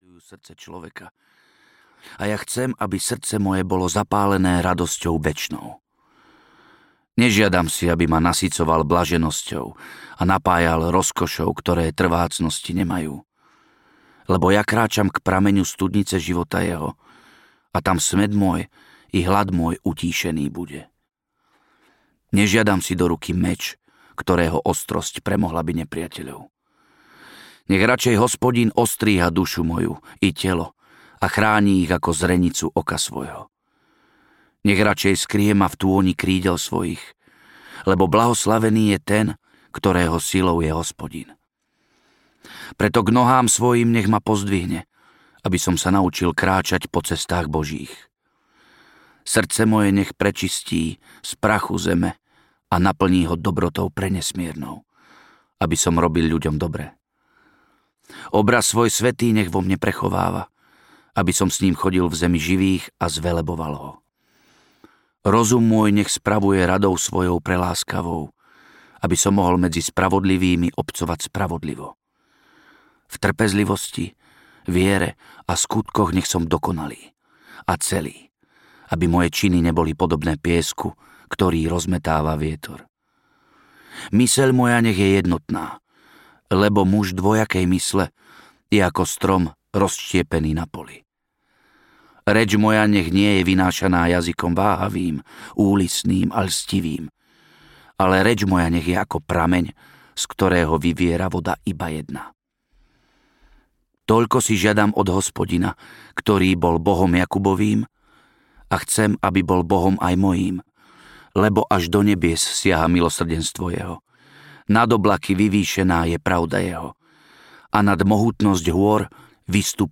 Tri gaštanové kone audiokniha
Ukázka z knihy